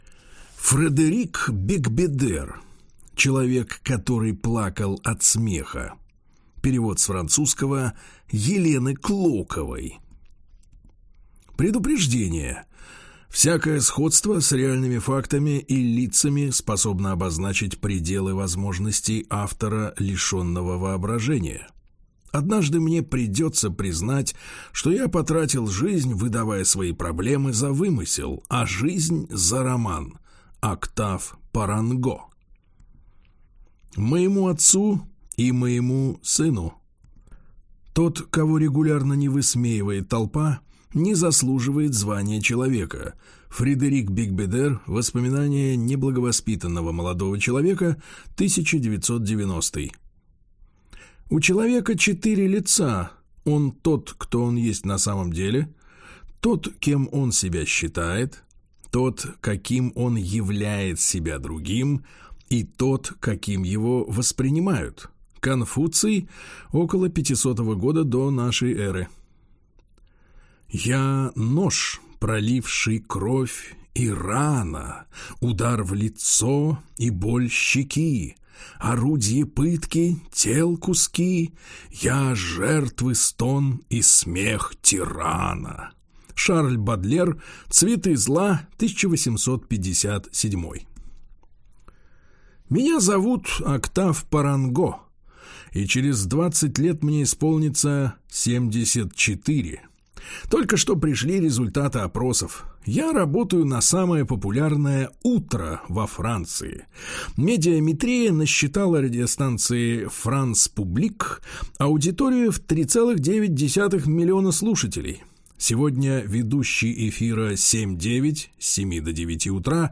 Аудиокнига Человек, который плакал от смеха | Библиотека аудиокниг